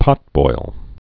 (pŏtboil)